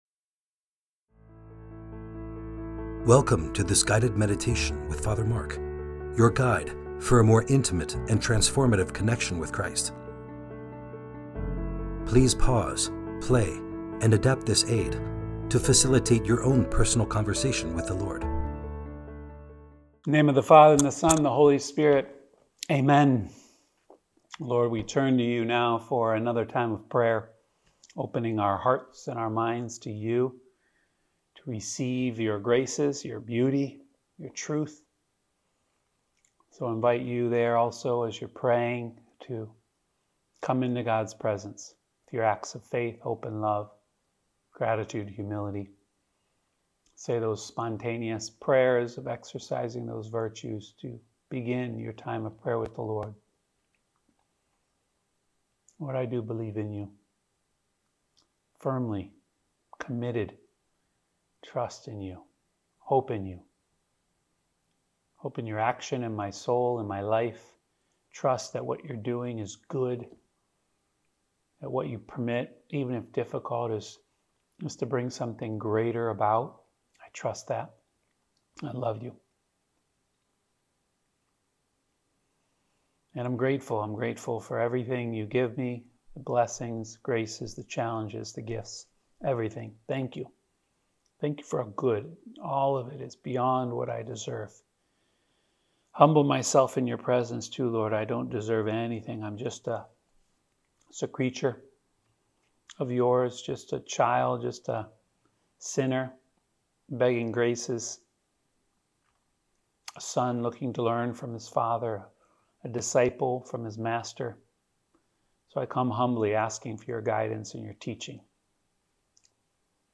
Friday Meditation